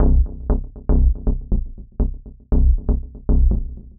Lab Work (Bass) 120BPM.wav